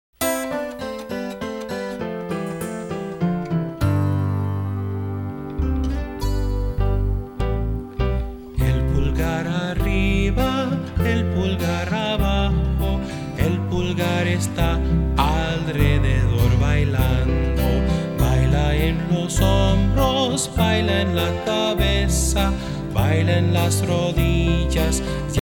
My all-time favorite calming finger play.